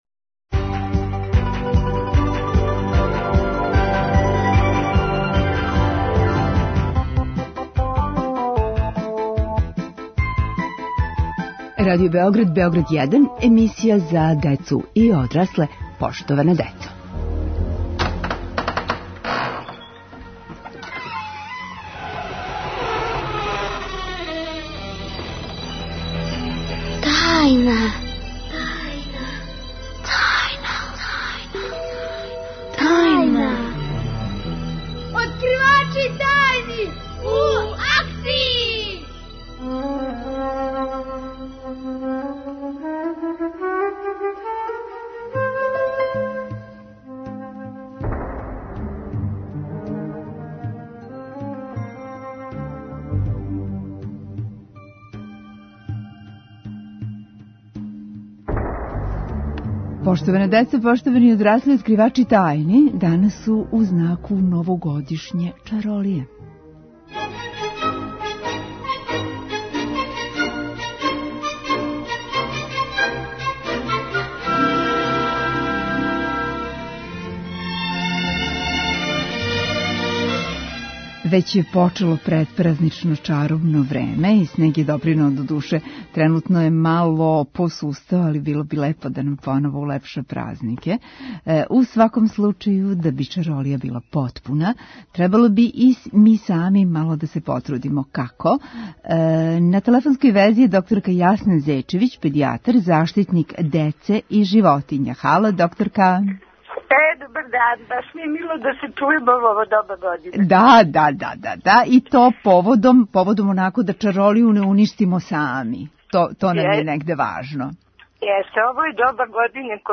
Улазимо у тајне новогодишње чаролије. У томе нам помажу: Заштолог, Зоотајнолог, Шумолог и Шумски дописник.